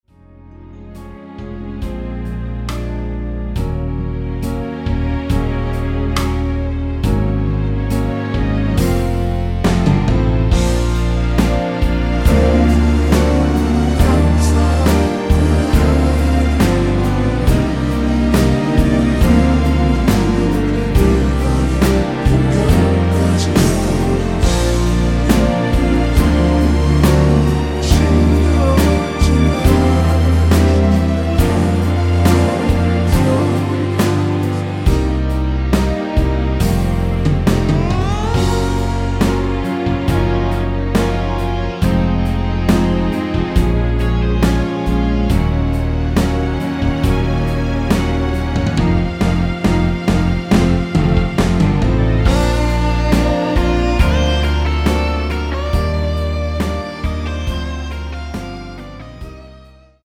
원키에서(-3)내린 코러스 포함된 MR 입니다.(미리듣기 참조)
Db
앞부분30초, 뒷부분30초씩 편집해서 올려 드리고 있습니다.
중간에 음이 끈어지고 다시 나오는 이유는